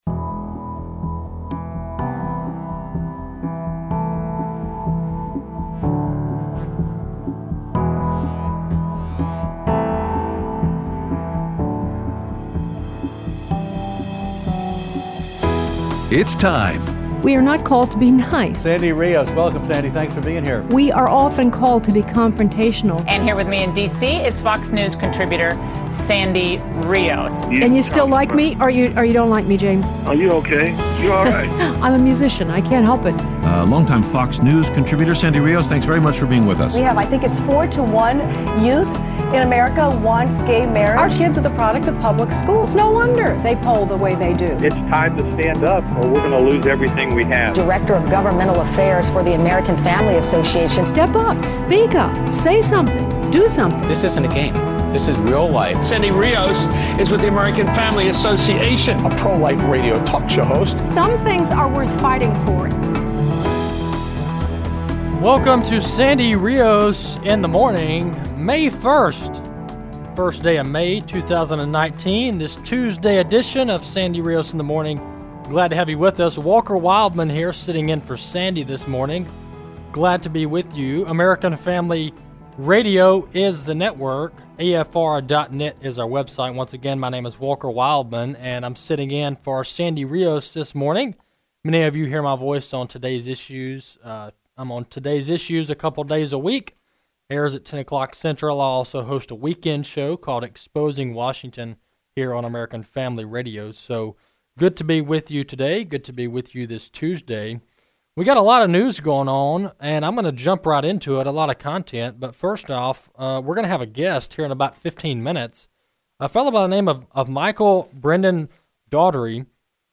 and Took Your Phone Calls